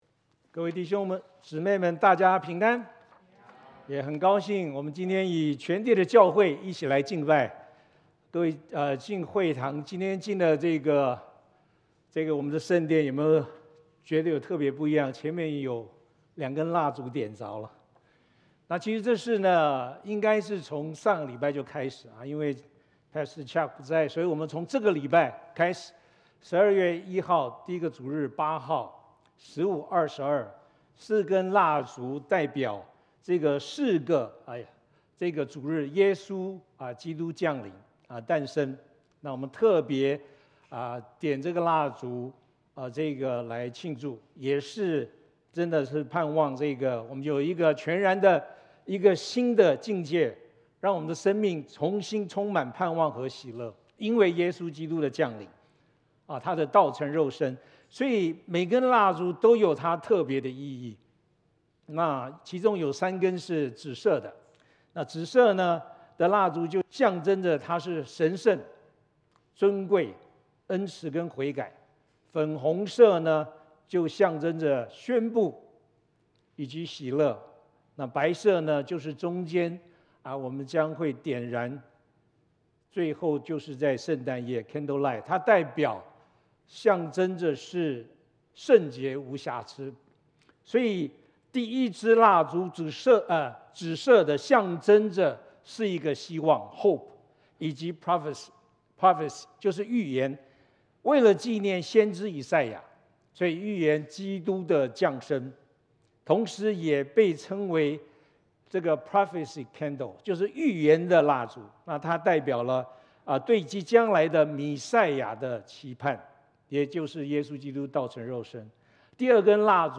證道錄音 | 拉法葉華人宣道會